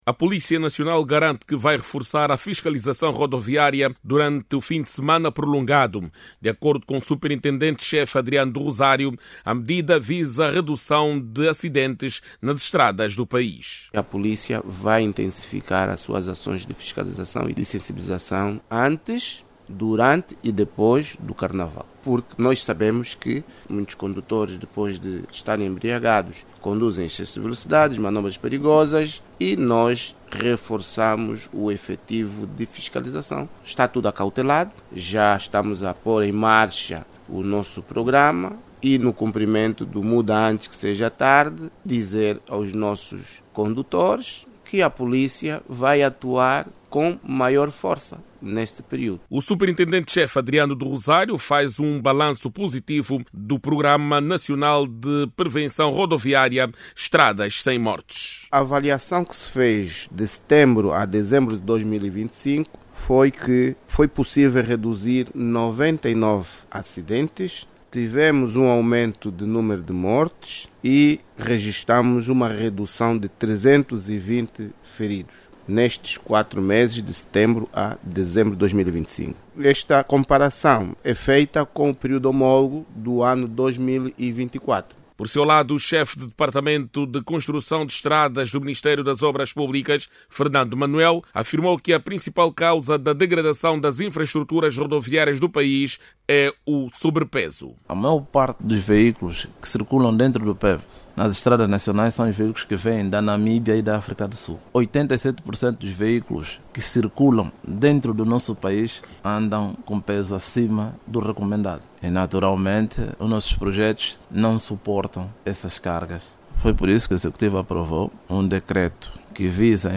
Ouça o desenvolvimento desta matéria na voz da jornalista